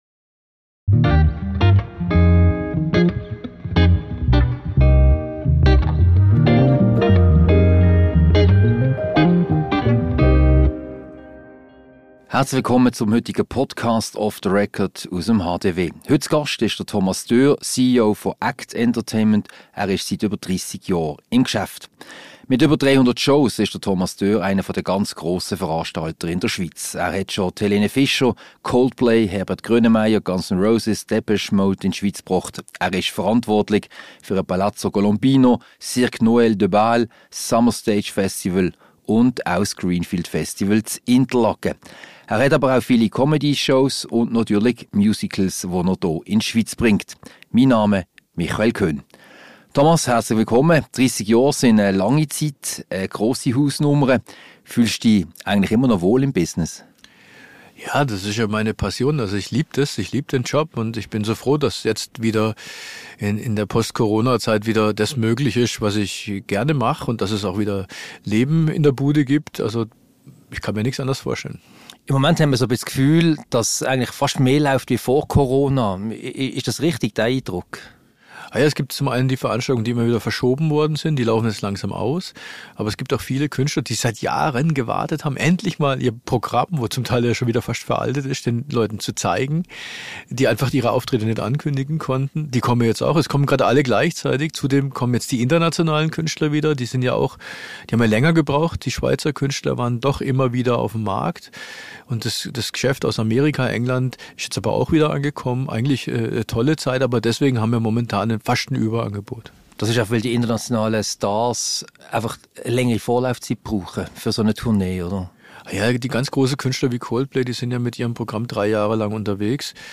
Ein Gespräch über das Event-Business, den Standort Basel und den Umgang mit Künstlern.